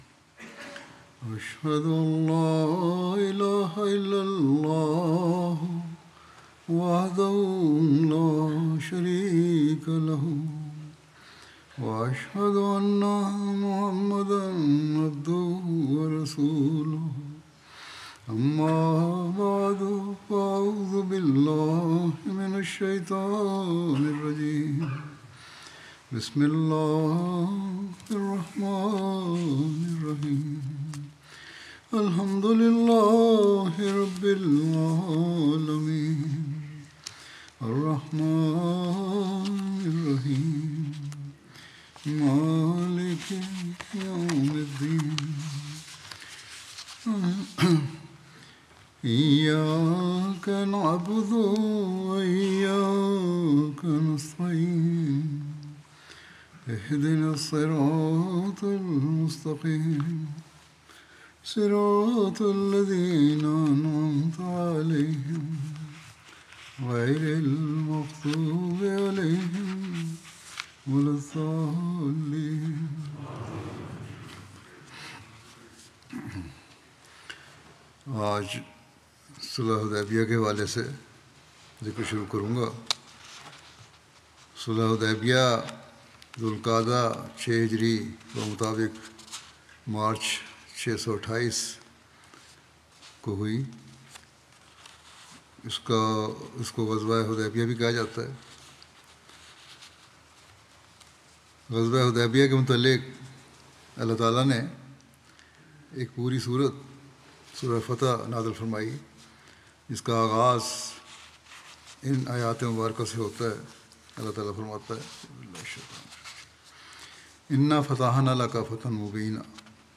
Urdu Friday Sermon delivered by Khalifatul Masih